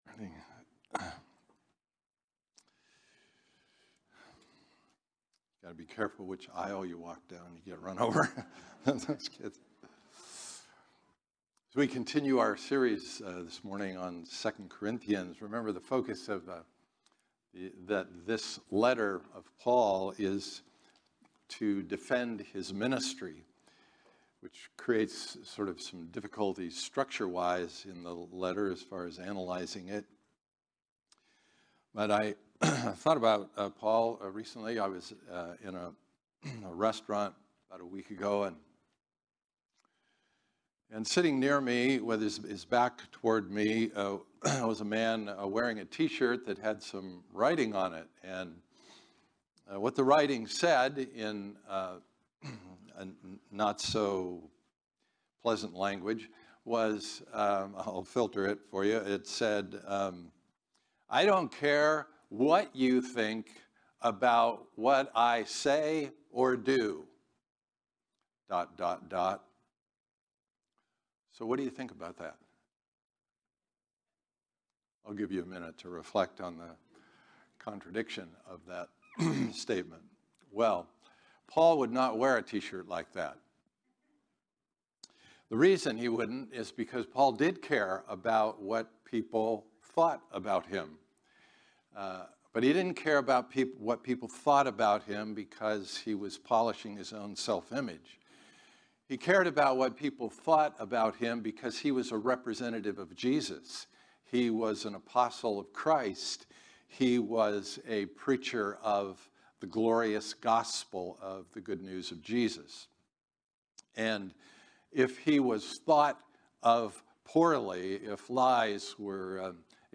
Type: Sermons